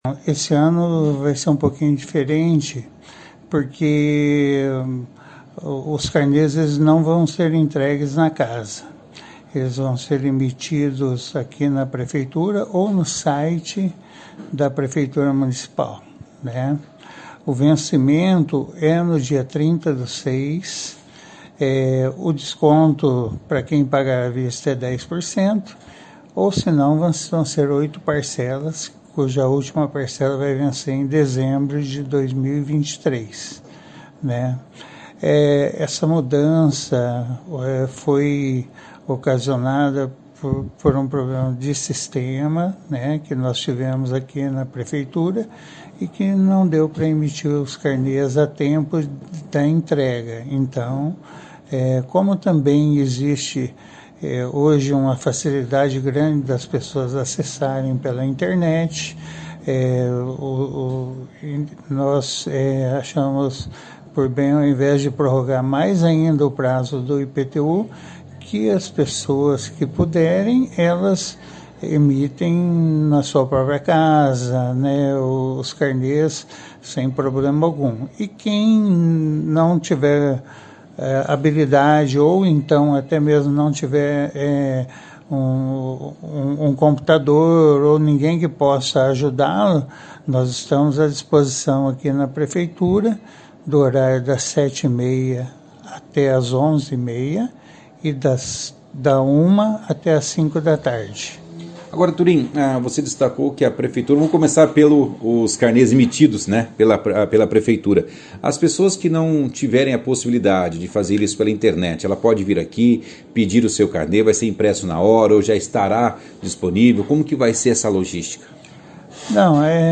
participou da 2ª edição do jornal Operação Cidade desta segunda-feira, 05/06, falando sobre o vencimento do pagamento do IPTU 2023, que será prorrogado para o dia 30 de junho.